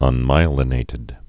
(ŭn-mīə-lĭ-nātĭd)